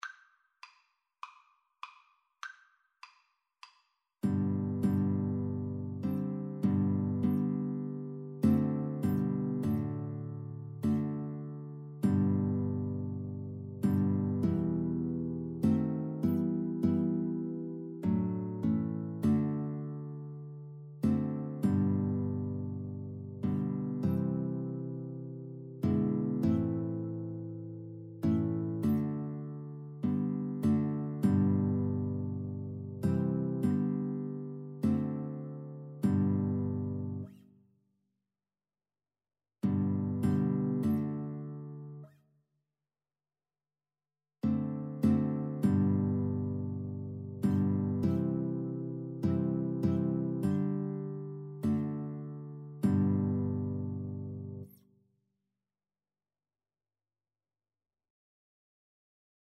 Free Sheet music for Violin-Guitar Duet
G major (Sounding Pitch) (View more G major Music for Violin-Guitar Duet )
4/4 (View more 4/4 Music)
Classical (View more Classical Violin-Guitar Duet Music)